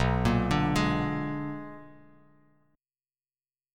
BmM13 chord